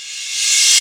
43_03_revcymbal.wav